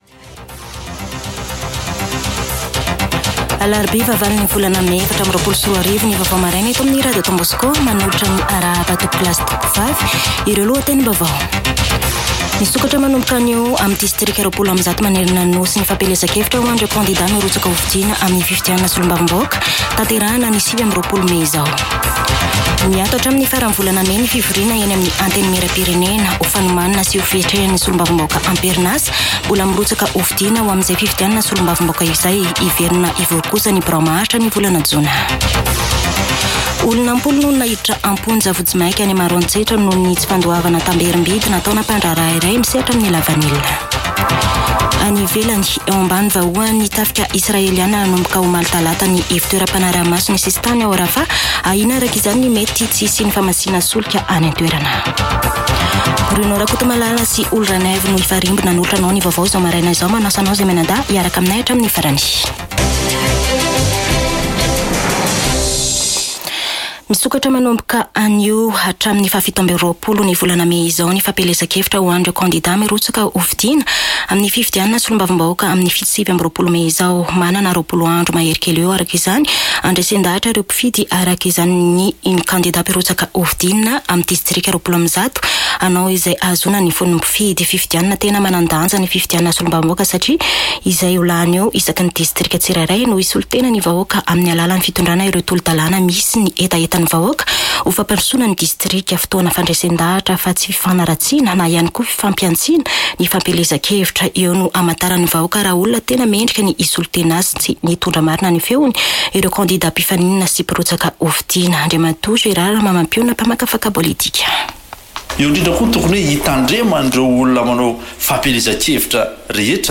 [Vaovao maraina] Alarobia 8 mey 2024